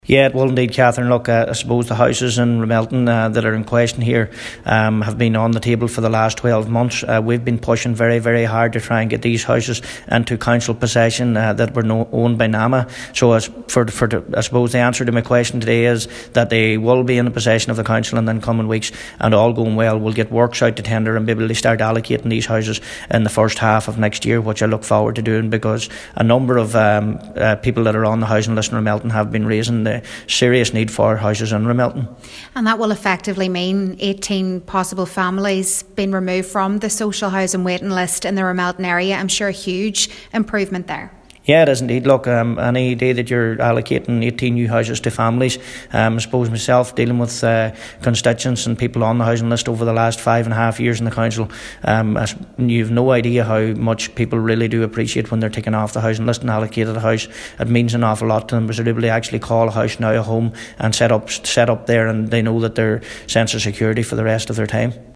Cathaoirleach of the Letterkenny Municipal District Cllr John O’Donnell says it will make a huge difference to the housing list locally: